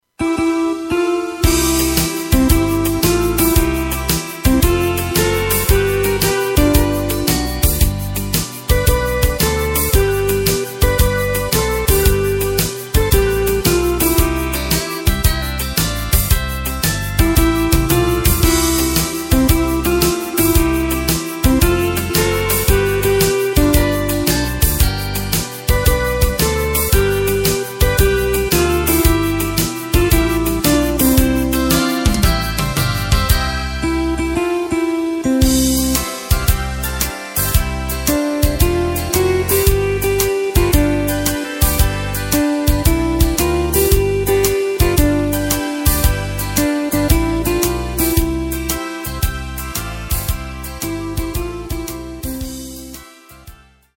Takt:          4/4
Tempo:         113.00
Tonart:            C
Schlager aus dem Jahr 1991!